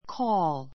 kɔ́ːl